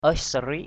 /əɪ’s-sa-ri:ʔ/ (d.) một trong các giờ hành lễ của đạo Bani (2 giờ chiều) = une des heures de célébration des cam Bani (env. 2 h. de l’après-midi). a celebratory hours...